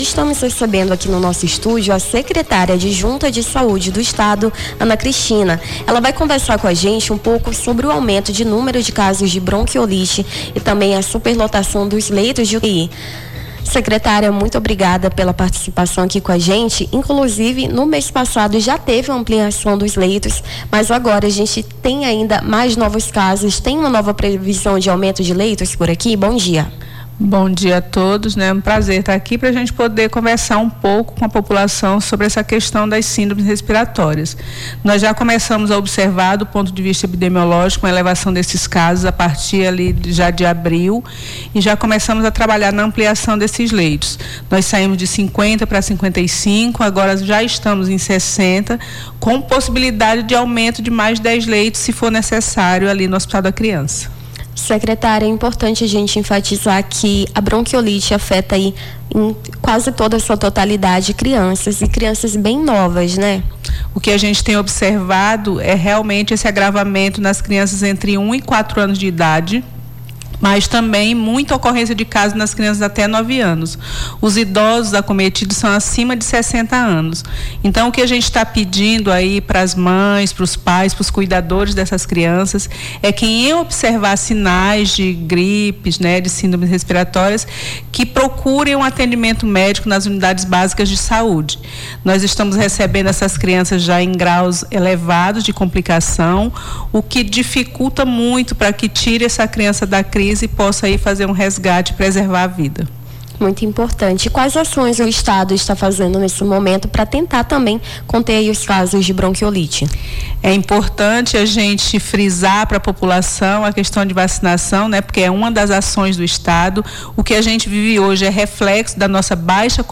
Nome do Artista - CENSURA - ENTREVISTA BRONQUIOLITE (13-05-25).mp3